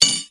餐具的声音 " 大勺子7
Tag: 餐具